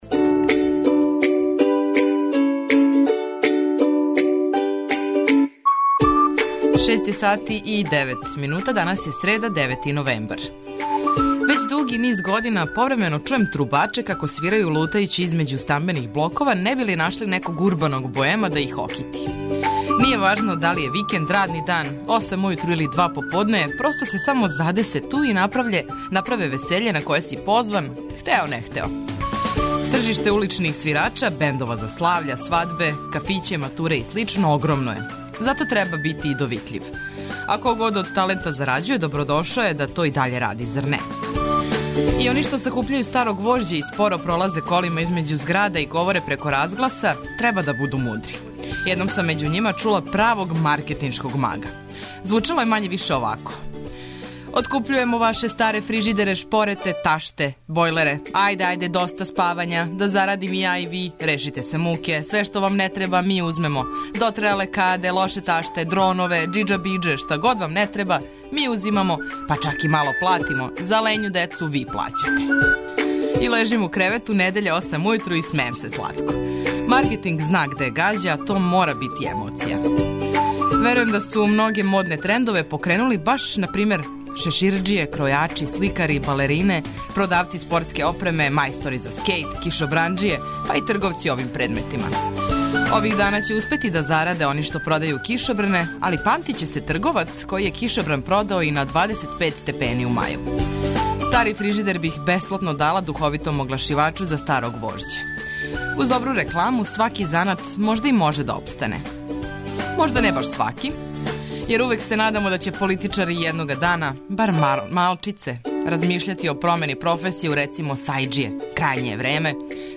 Прве сате јутра испунили смо музиком за разбуђивање и информацијама које су важне.